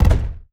EXPLDsgn_Explosion Impact_04_SFRMS_SCIWPNS.wav